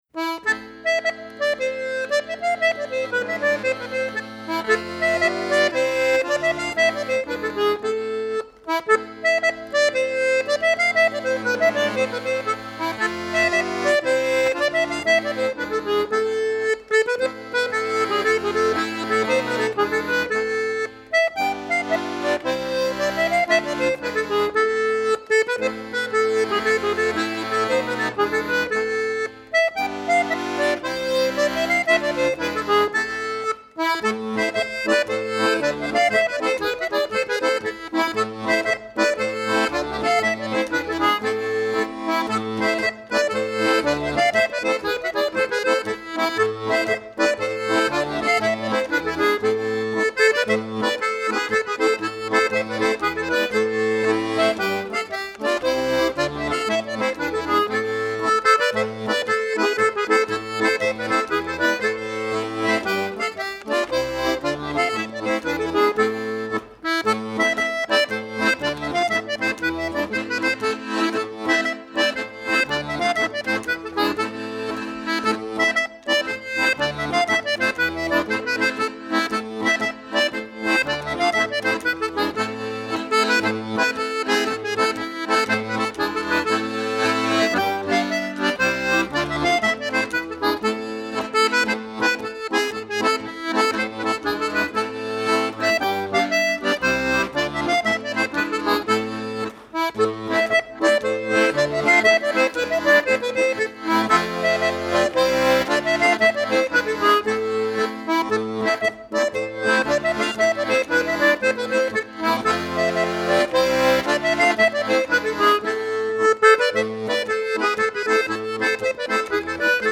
contrebasse
gralla
clarinette
cistre
trad wallon